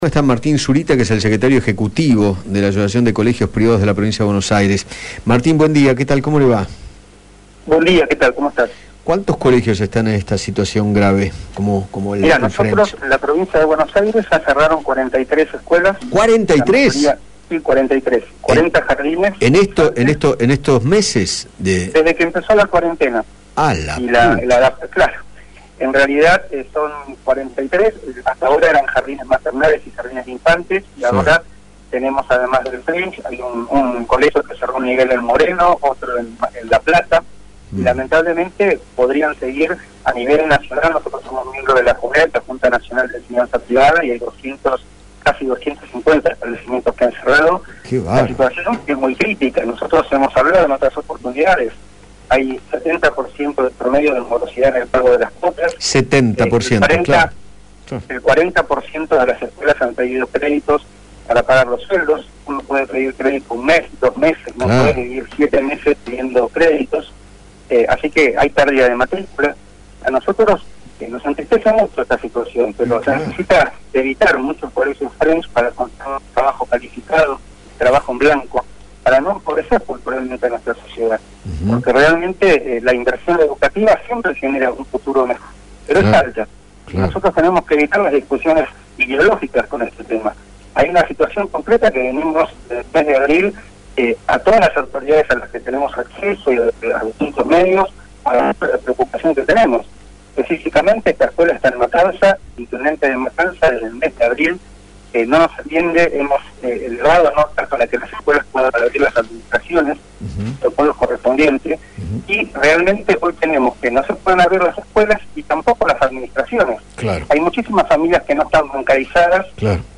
conversó con Eduardo Feinmann sobre la crítica situación que atraviesan los institutos privados y contó que “hay 70% de morosidad en el pago de las cuotas”.